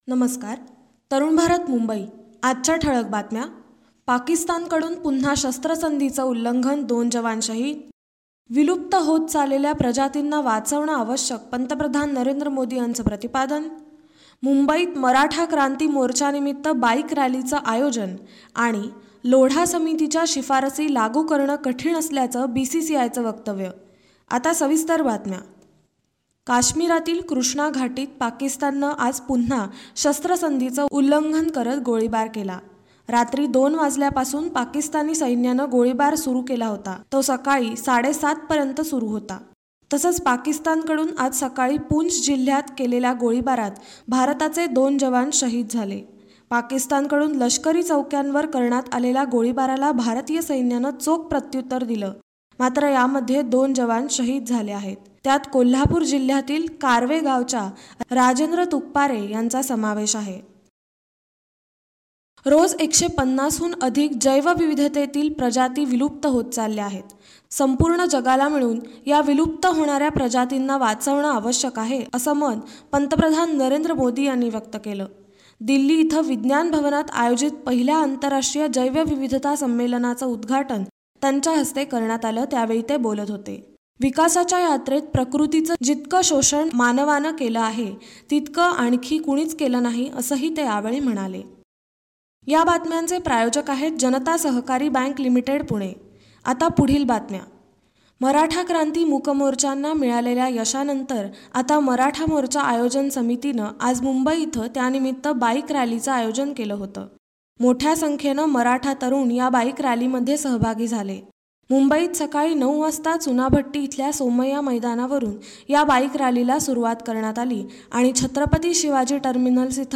संध्याकाळचे बातमीपत्र ६ नोव्हेंबर २०१६